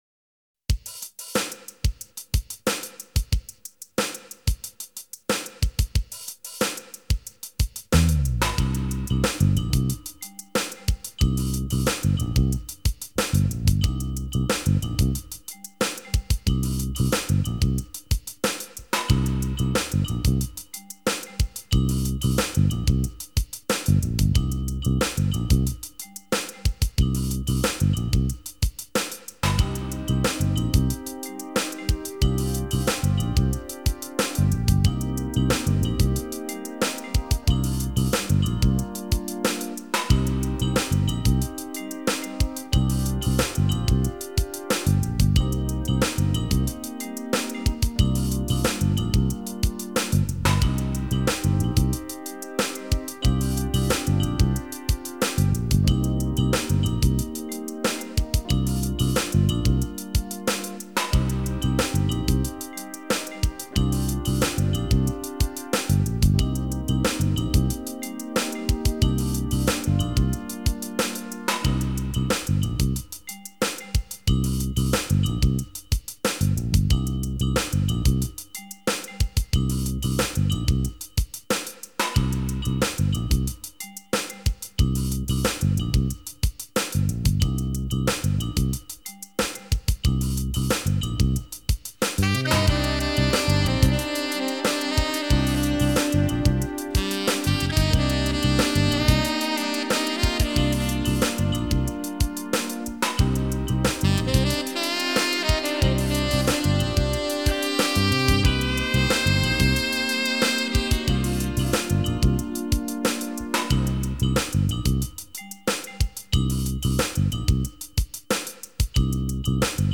Genre: Instrumental Pop.